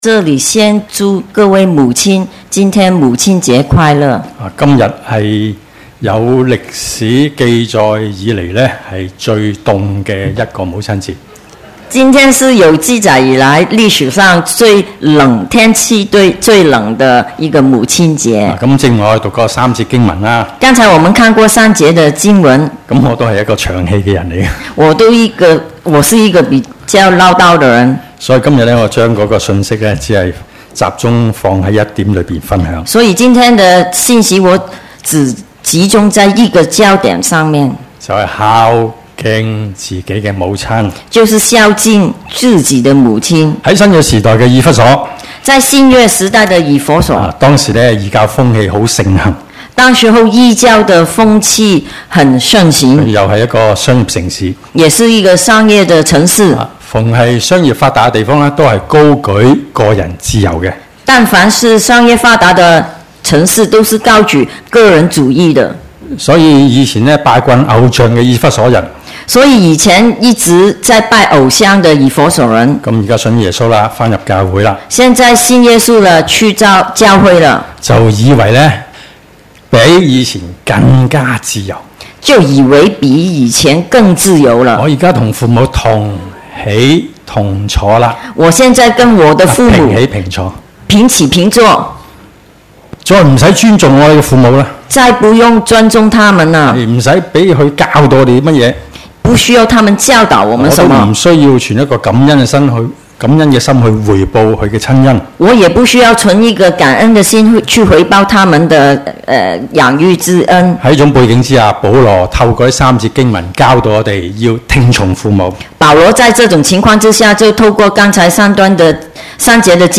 Posted in 主日崇拜 0 Comments Add a Comment Cancel 發佈留言必須填寫的電子郵件地址不會公開。